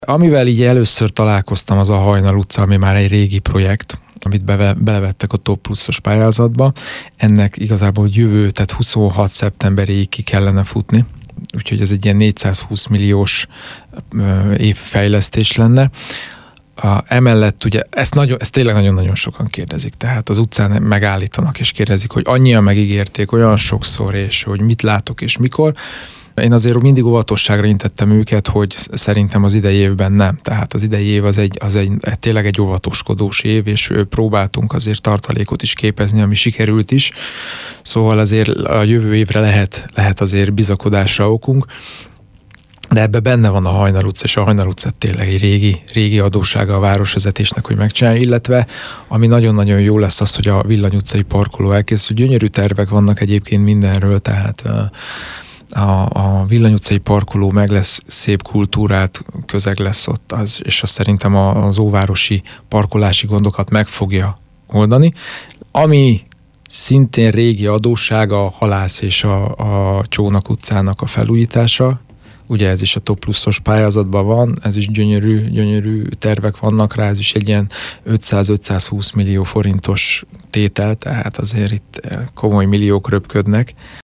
A Hajnal utca is megújul Európai Uniós forrásból, erről Nagy Balázs a 2-es körzet önkormányzati képviselője beszélt rádiónknak. A TOP Plusz pályázat keretében az önkormányzat több fejlesztésre nyert forrást, a képviselő ezekre is kitért tájékoztatójában.